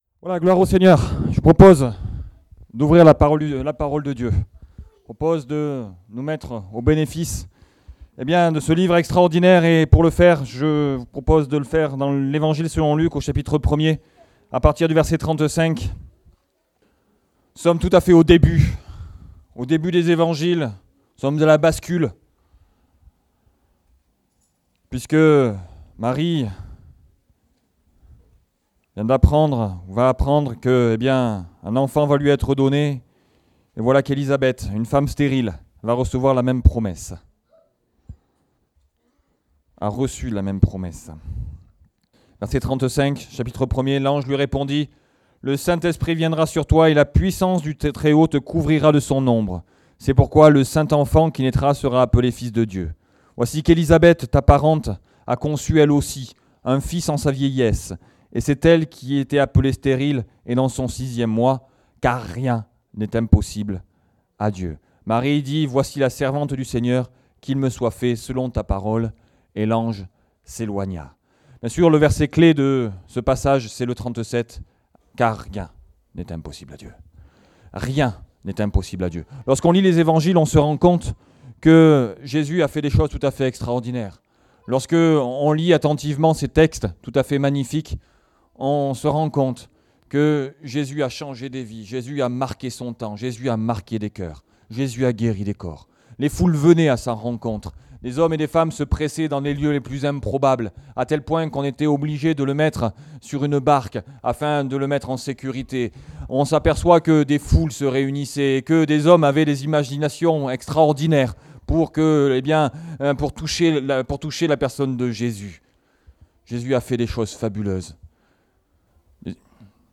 Category: Message audio